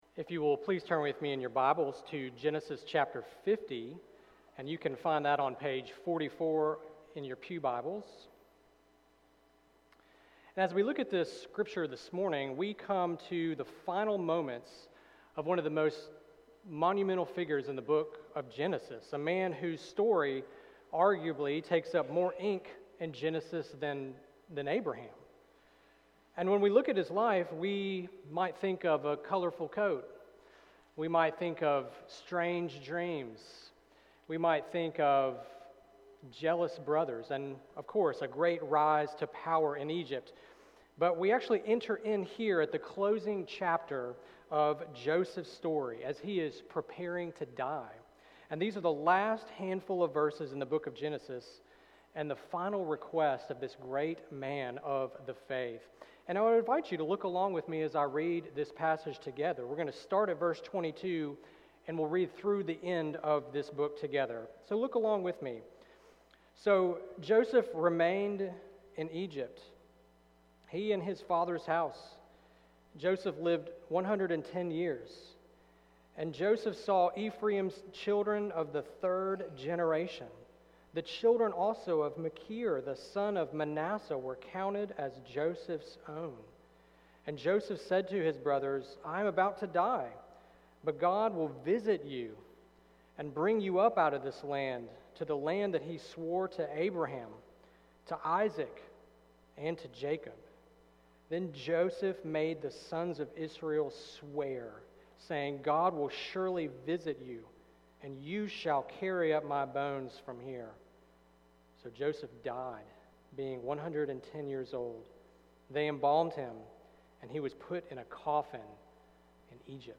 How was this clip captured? Our sermon this Sunday morning will center around the last handful of verses in the first book of the Bible, Genesis 50:22-26.